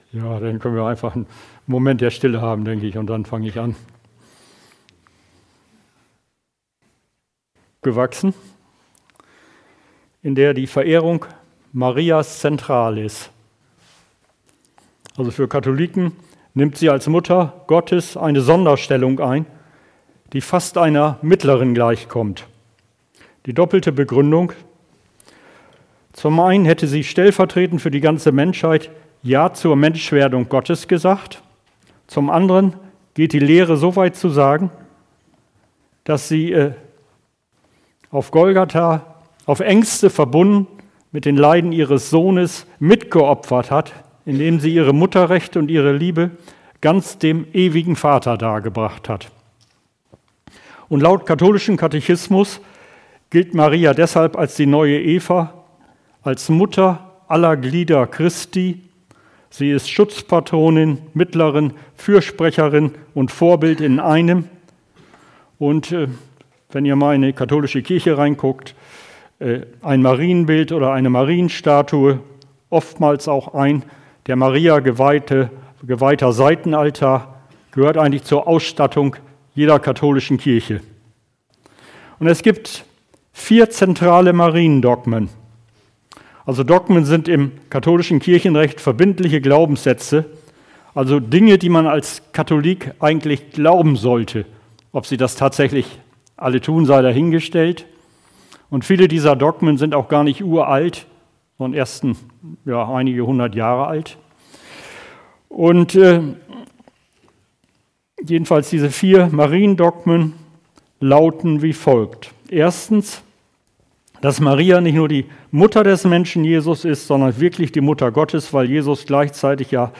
Predigten 2026 - Gemeinde am Mühlenweg
Predigten 2026 Predigten 2026 Hier finden Sie die Predigten aus dem Jahr 2026, auch zum Download als mp3.